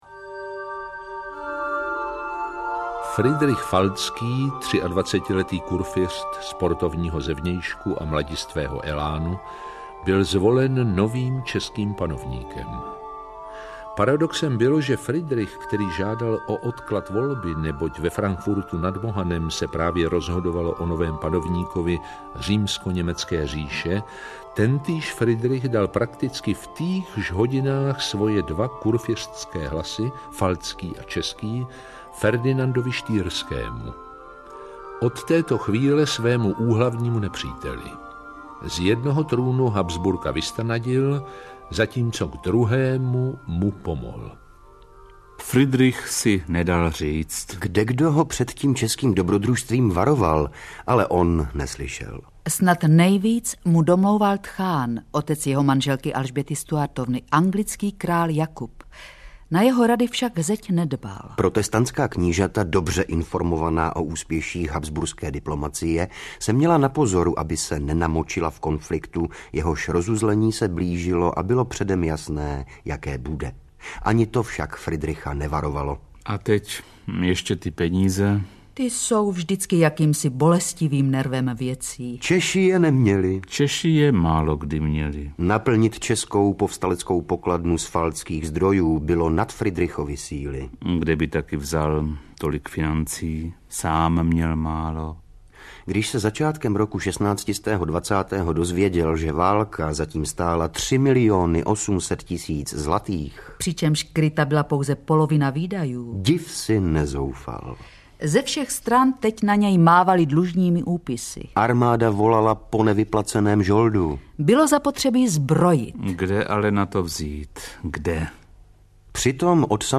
Toulky českou minulostí 401 - 450 audiokniha
Ukázka z knihy